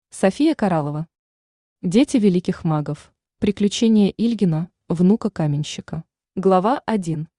Аудиокнига Дети великих магов | Библиотека аудиокниг
Aудиокнига Дети великих магов Автор София Коралова Читает аудиокнигу Авточтец ЛитРес.